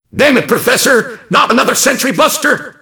mvm_sentry_buster_alerts03.mp3